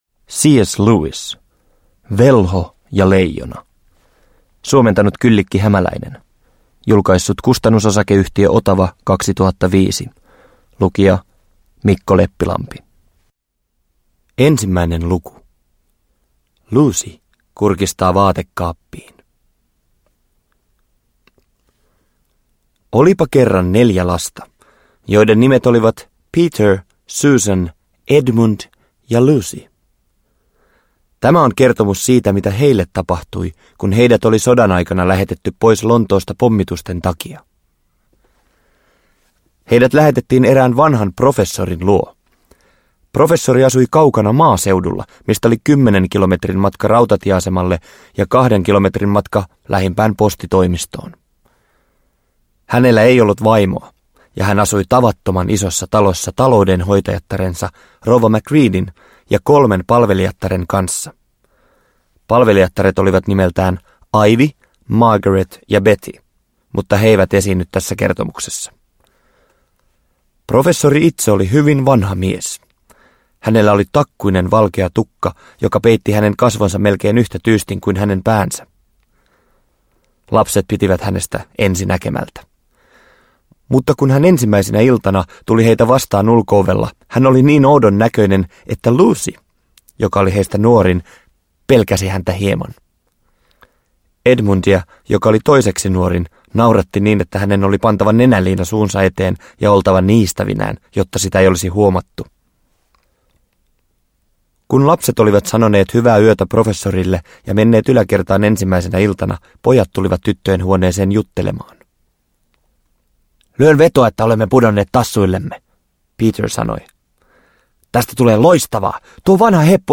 Velho ja leijona – Ljudbok – Laddas ner
Uppläsare: Mikko Leppilampi